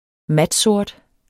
Udtale [ -ˈsoɐ̯d ]